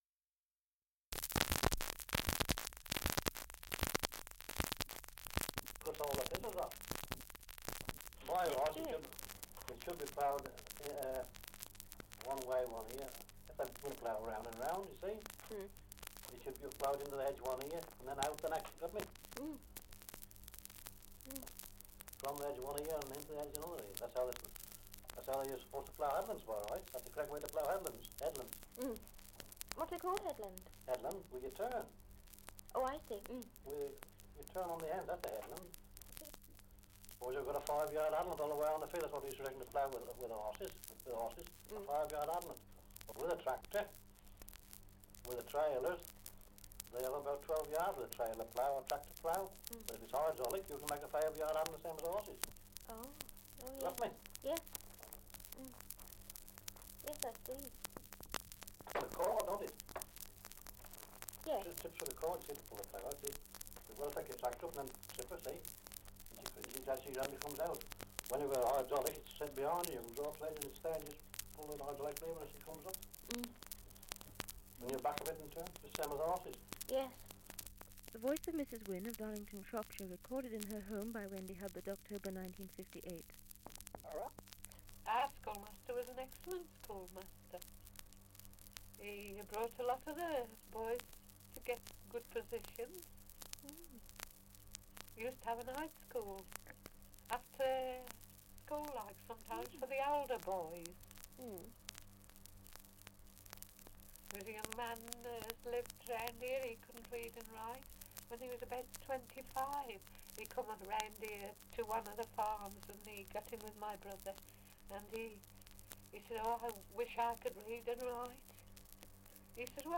2 - Dialect recording in Albrighton, Shropshire
78 r.p.m., cellulose nitrate on aluminium